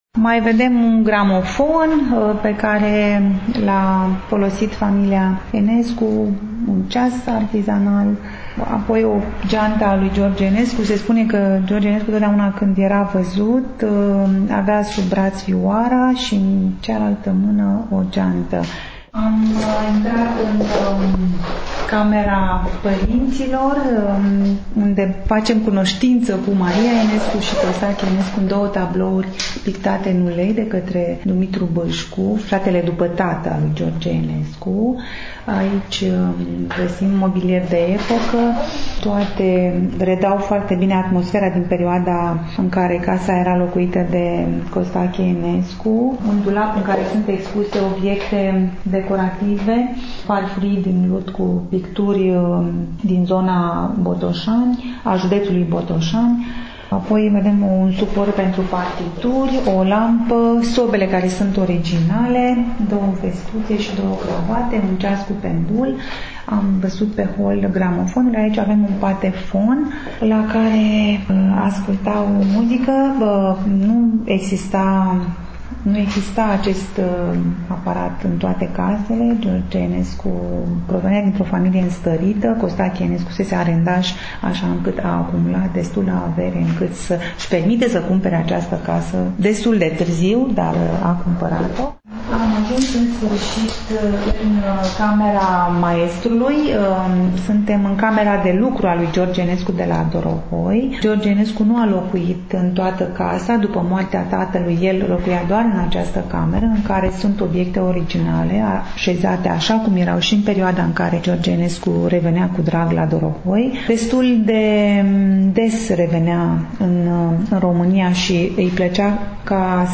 După cum bine știți, astăzi… poposim în incinta Muzeului Memorial „George Enescu” din Dorohoi. Enescu a păstrat această casă pentru că venea adeseori la Dorohoi, aici găsea locul potrivit pentru odihnă şi pentru creaţie.